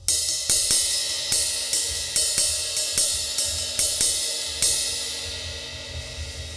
Twisting 2Nite Drumz Ride.wav